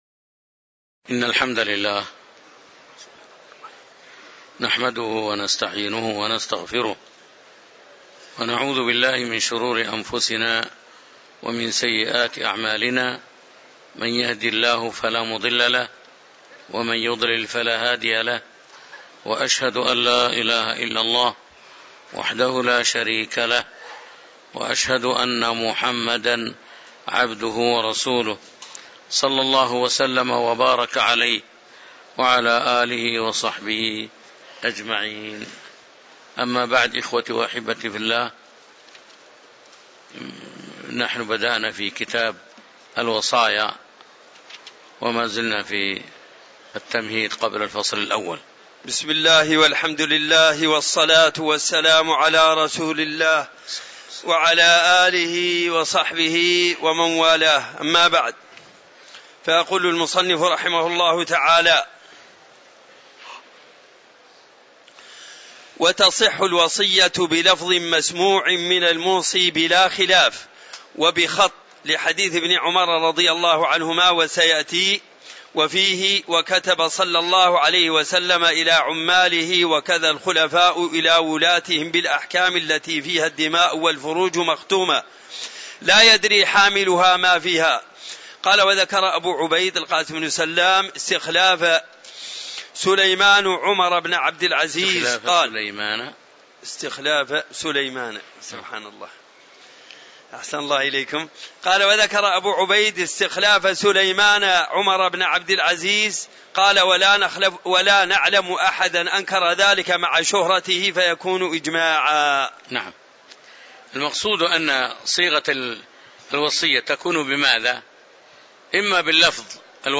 تاريخ النشر ٢٦ شوال ١٤٤٤ هـ المكان: المسجد النبوي الشيخ